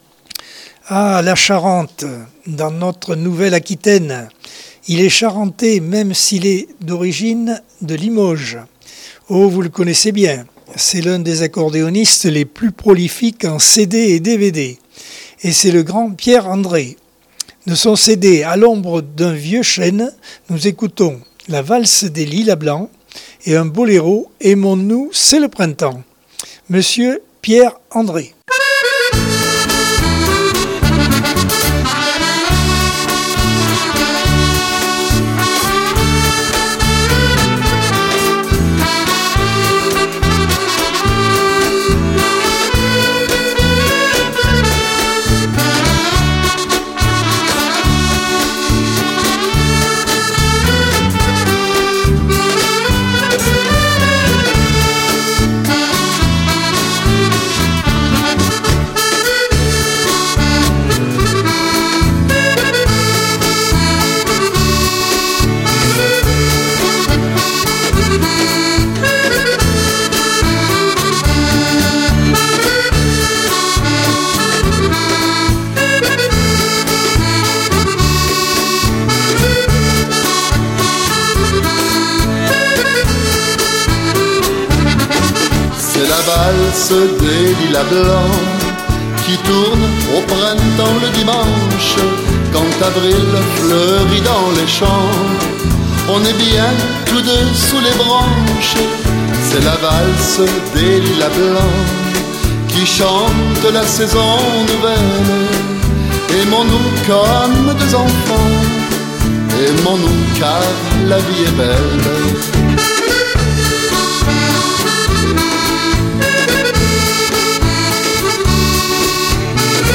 Accordeon 2022 sem 24 bloc 4.